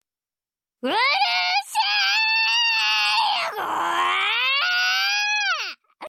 Angry Anime Girl Rage Sound Effect Free Download
Angry Anime Girl Rage